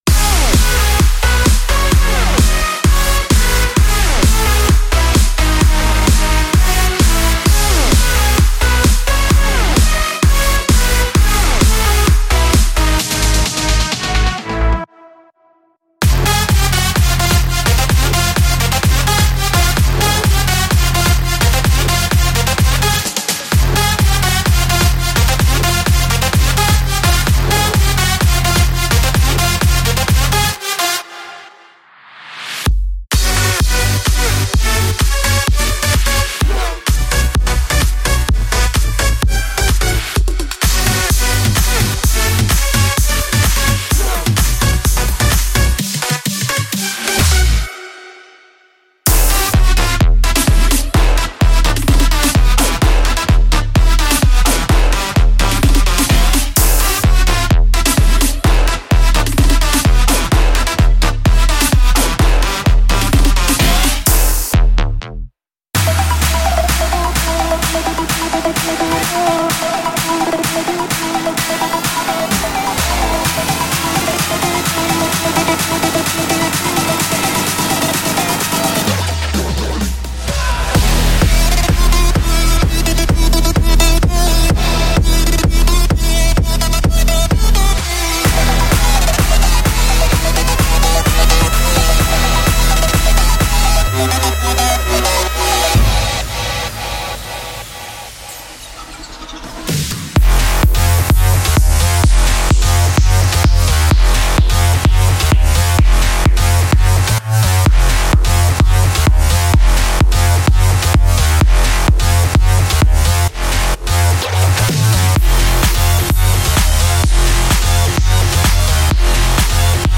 CLAPS[85个样本]
Classic Claps
Stadium Claps
填充(128 BPM)[15个样本]
节日踢[30个样本]
SNARES
合成镜头[160个样本]
vOCAL SHOTS[55个样本]